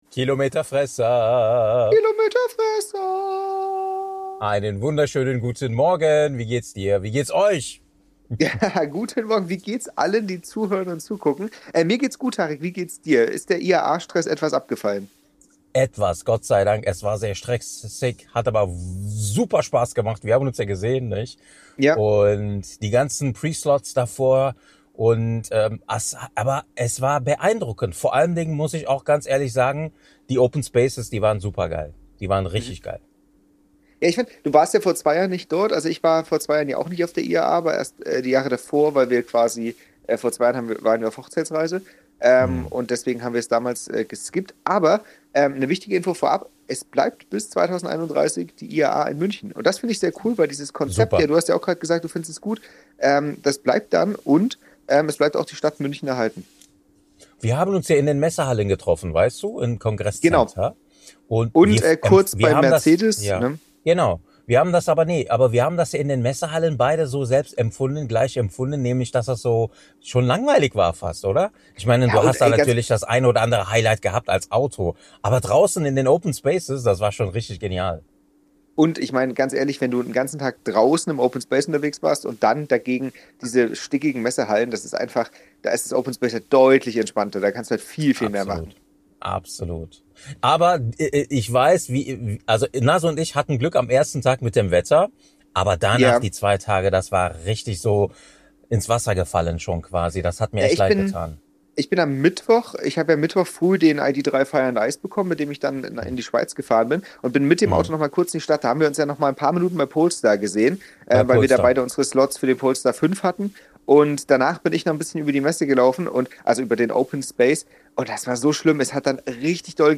die Kilometerfresser Politiker schlafen, Hersteller handeln (oder warten) - die Kilometerfresser #58 Play episode September 18 44 mins Bookmarks Episode Description Wir lassen die IAA Revue passieren und reden über unsere Highlights. Außerdem nehmen wir euch mit auf den Togg stand und geben euch live unsere Eindrücke!